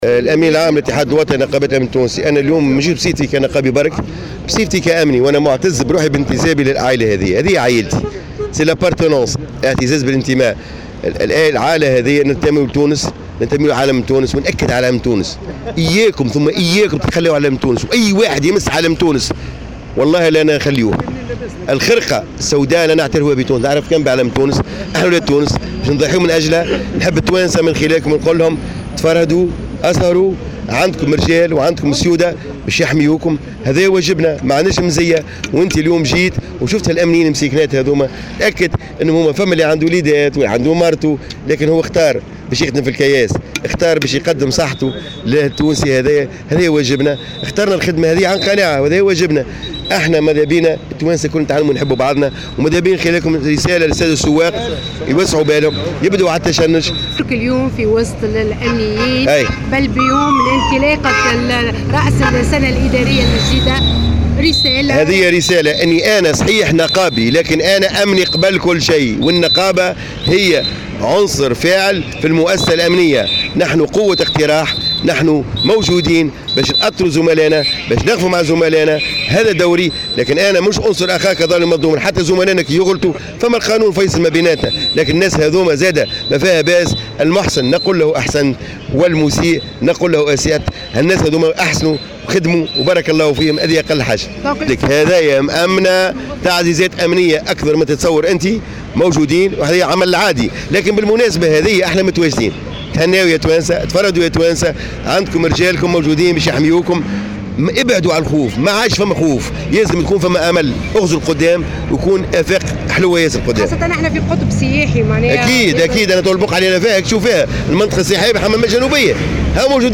تصريح لمراسلة "الجوهرة أف أم"